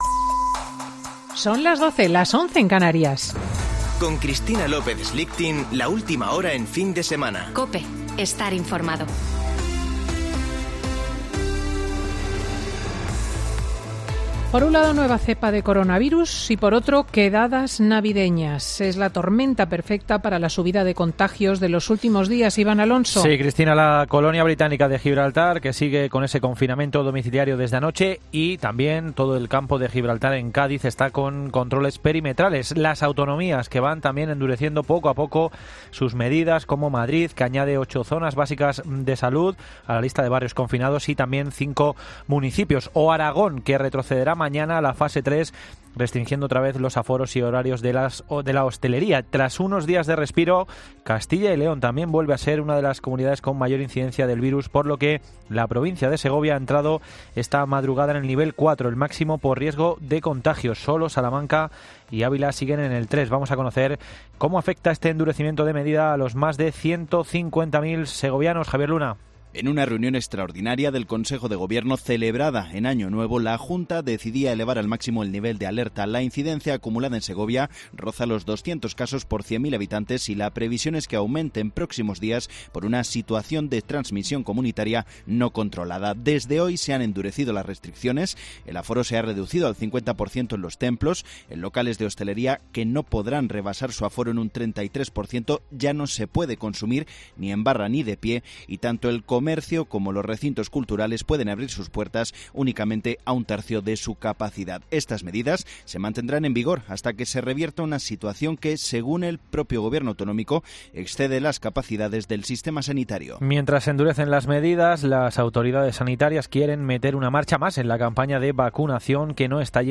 Boletín de noticias de COPE del 3 de enero de 2021 a las 13.00 horas